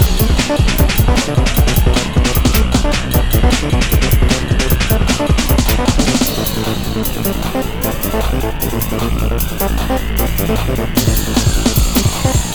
33 Drumbeat Goes-c.wav